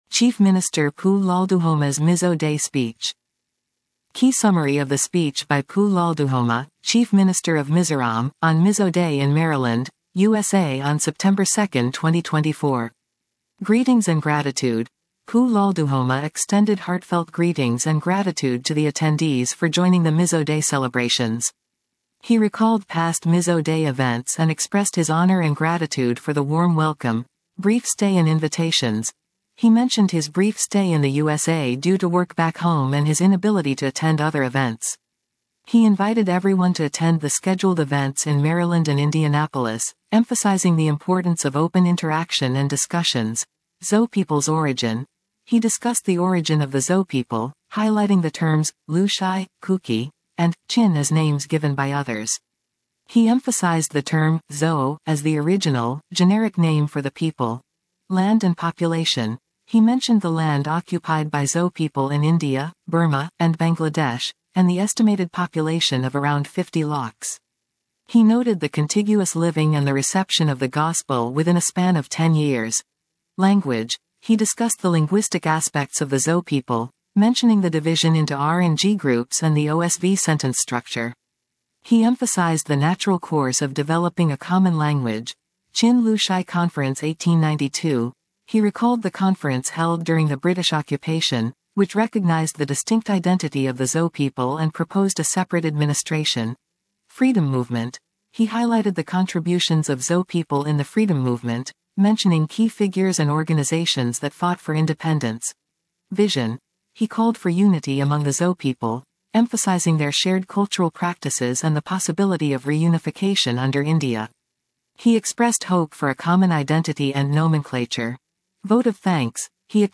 Key Summary of the speech by Pu Lalduhoma, Chief Minister of Mizoram, on Mizo Day in Maryland, USA on September 2, 2024.
SPEECH OF PU LALDUHOMA, CHIEF MINISTER, MIZORAM ON MIZO DAY, MARYLAND, USA, 2.09.2024